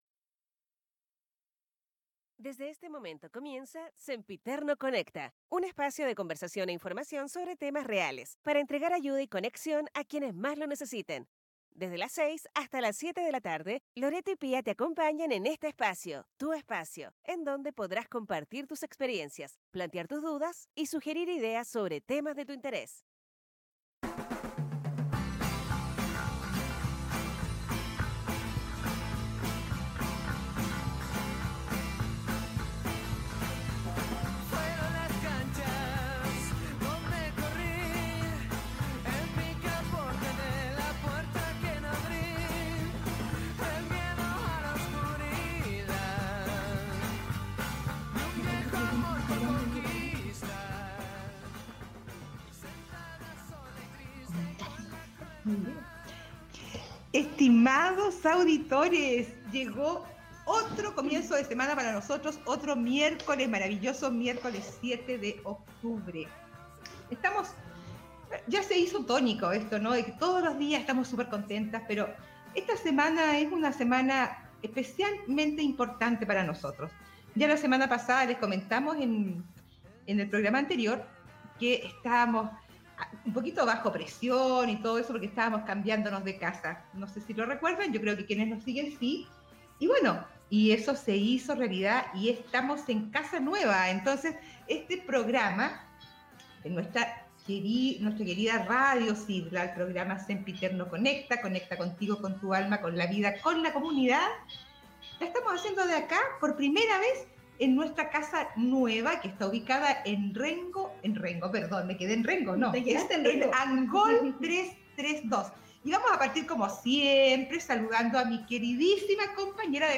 10mo capitulo del programa de radio digital: Sempiterno Conecta